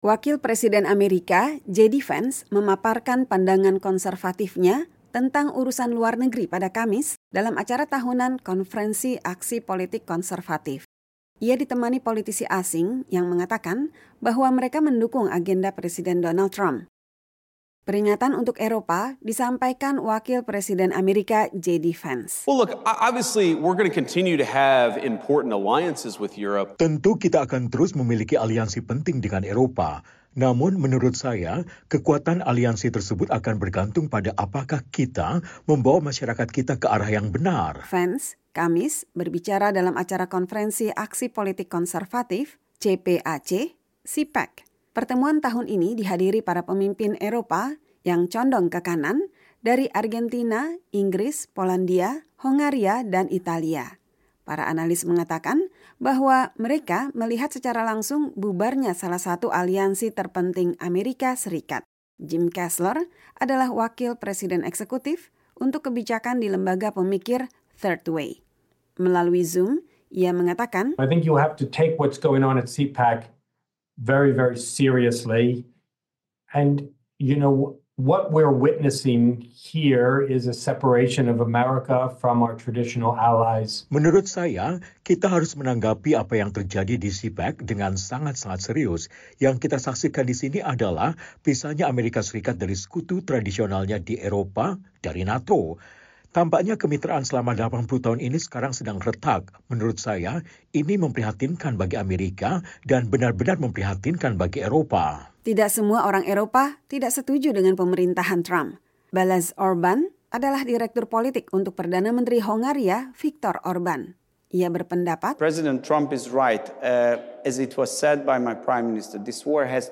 Wakil Presiden AS JD Vance berbicara selama Konferensi Aksi Politik Konservatif "CPAC 2025" di National Harbor, di pinggiran Washington DC, Kamis 20 Februari 2025.